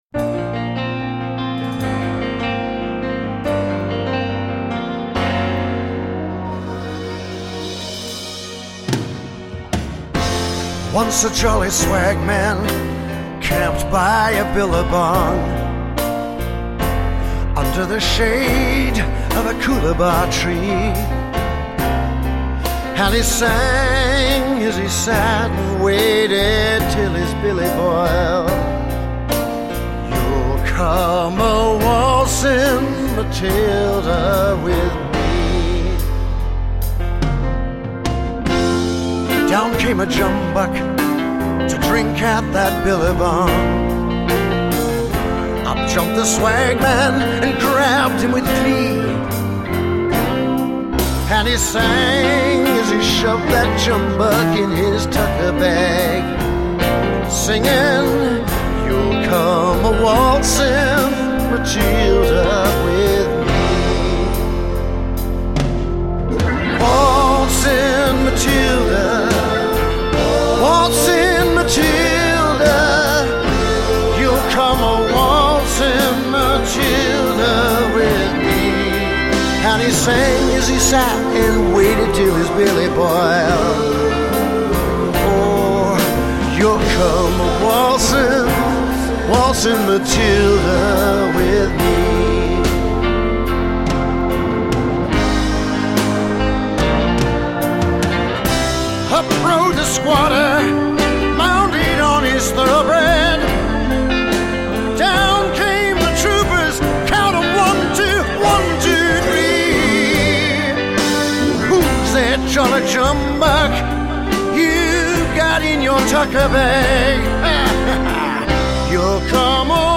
I started playing in a slower, rock style and realized it was pretty soulfull.  I quickly went and threw a MIDI arrangement into the computer.